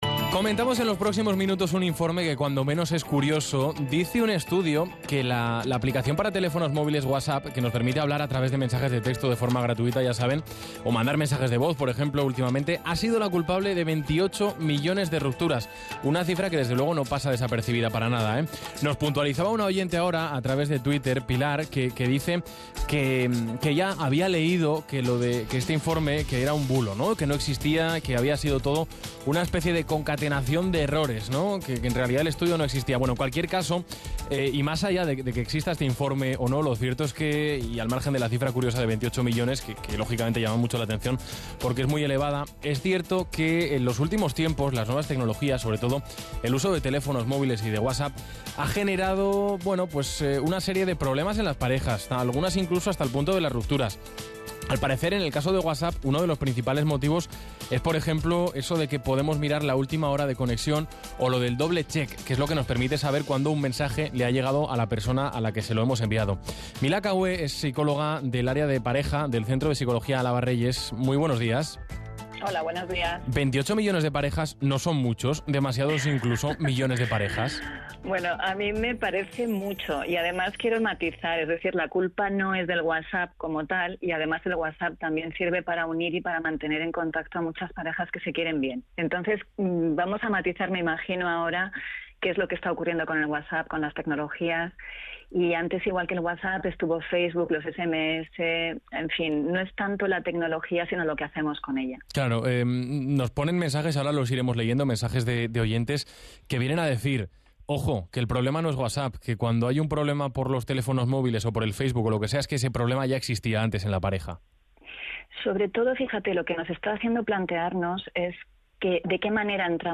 Os dejo a continuación la intervención de hoy, 15 de Octubre de 2013, en el programa «Esta es la nuestra» de Aragón Radio, en el que hemos estado hablando sobre el Whatsapp y los presuntos 28 millones de rupturas de parejas. ¿Es culpa del Whatsapp?¿Rompe o une?